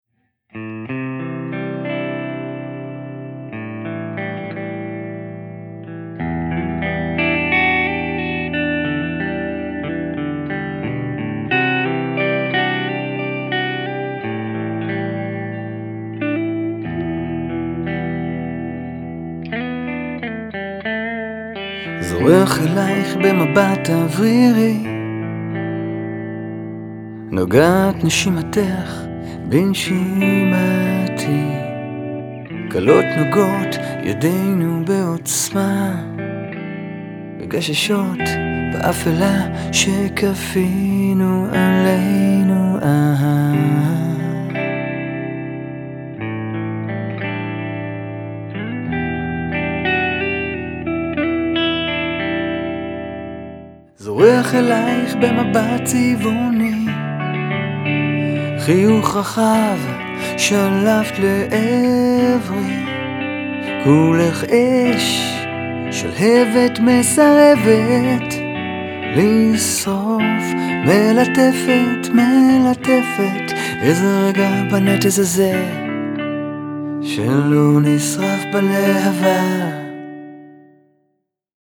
ז'אנר: Blues.